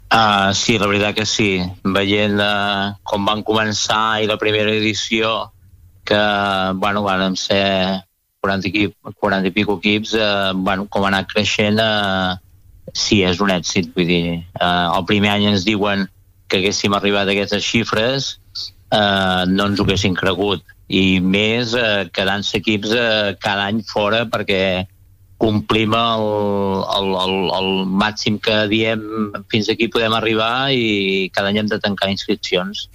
Entrevistes SupermatíCursesEsportsPalafrugell